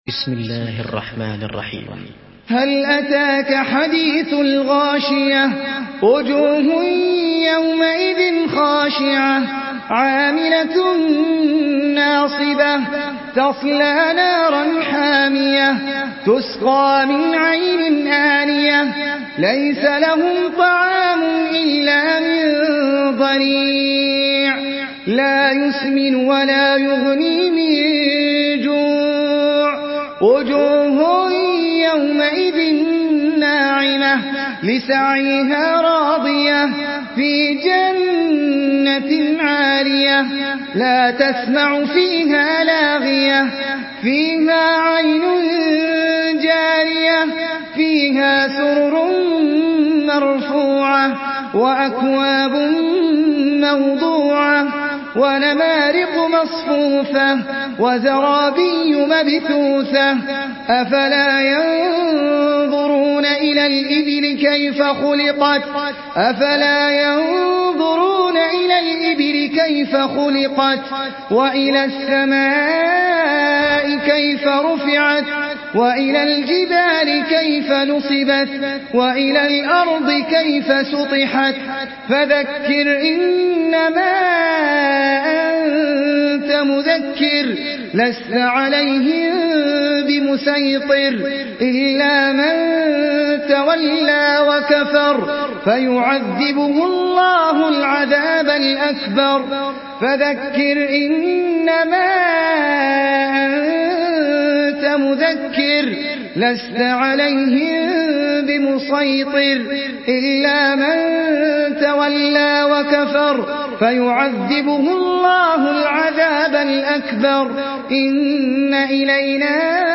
Surah Al-Ghashiyah MP3 in the Voice of Ahmed Al Ajmi in Hafs Narration
Murattal